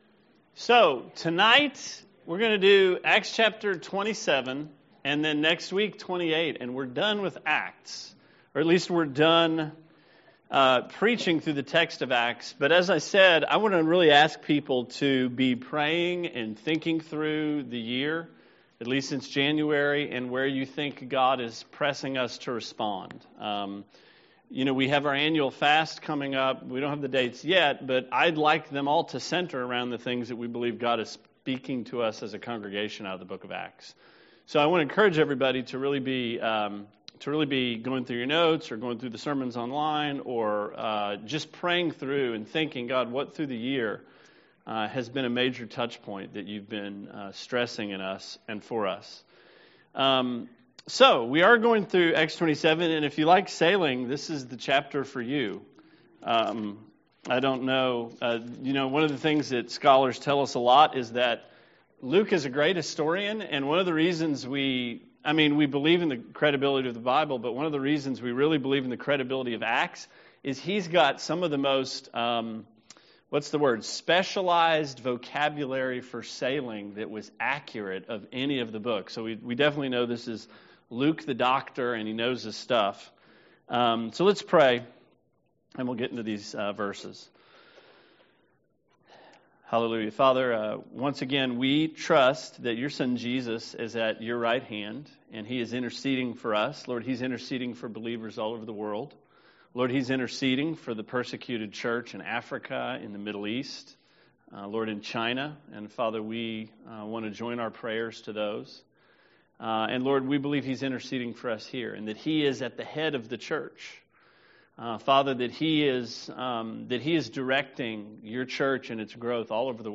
Sermon 10/7: Acts 27